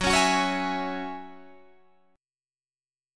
Listen to Gb7sus2 strummed